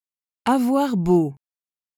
🎧 Avoir beau pronunciation
avwaʁ bo/, which sounds like ah-vwahr boh.